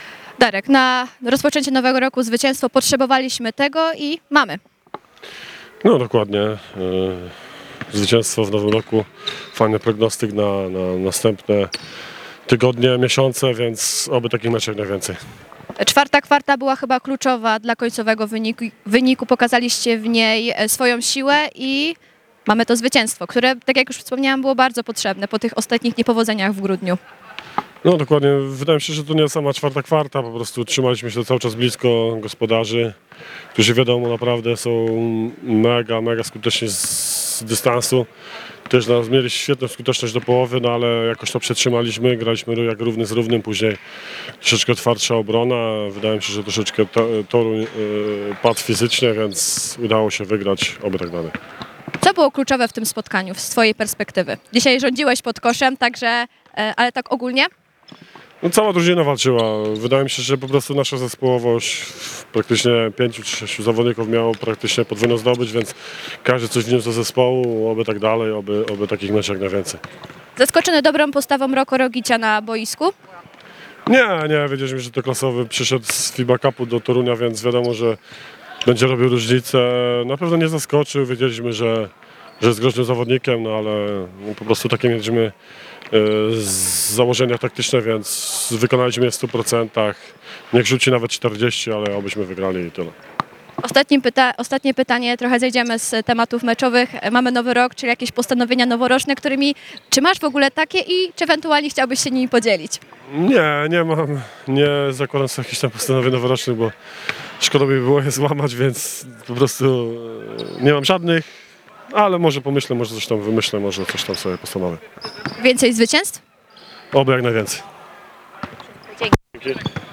Twarde Pierniki Toruń – Legia Warszawa: Pomeczowe wypowiedzi legionistów